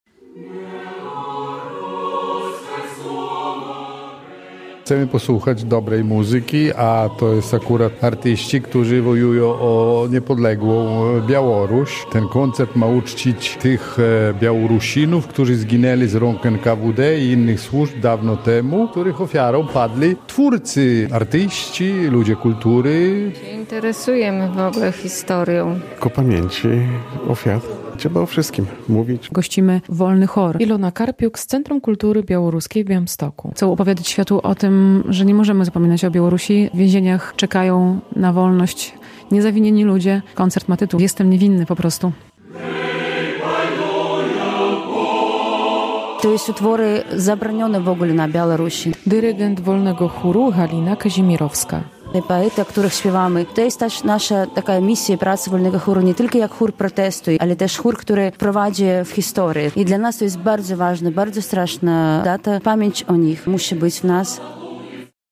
Koncert w rocznicę zamordowania 130 przedstawicieli białoruskiej inteligencji